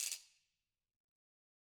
Ratchet1-Crank_v1_rr2_Sum.wav